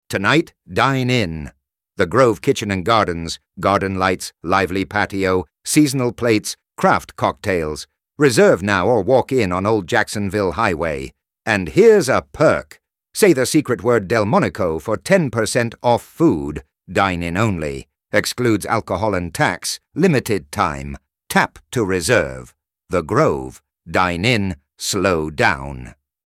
The Grove — dine-in promo with secret word.
the_grove_radio_ad_for_blog.mp3